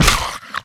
PixelPerfectionCE/assets/minecraft/sounds/mob/skeleton/hurt2.ogg at mc116